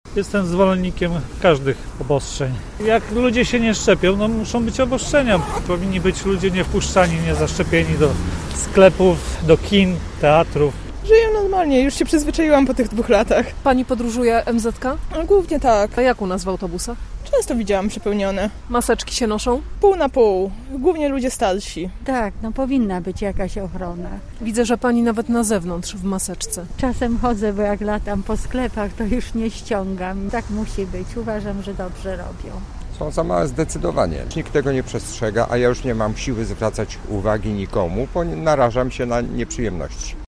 O zdanie na temat nowych regulacji zapytaliśmy też mieszkańców: https